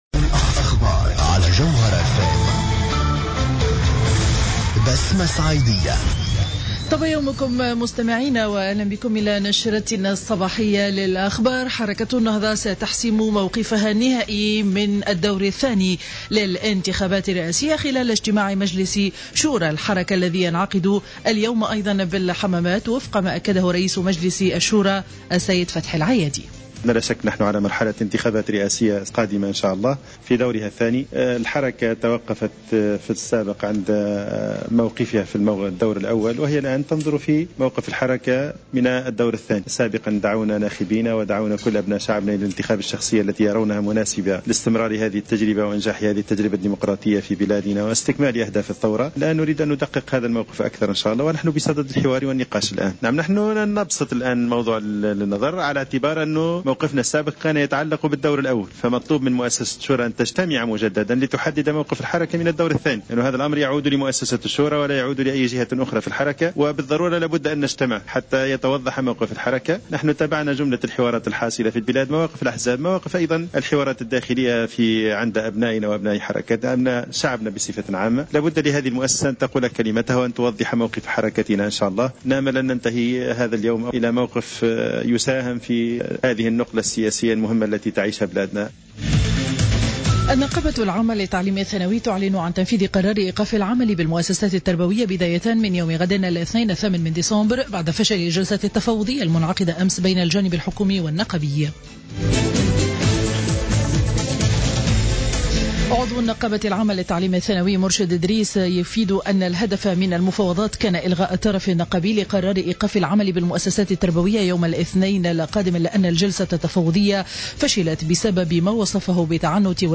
نشرة الأخبار السابعة صباحا ليوم الأحد 07-12-14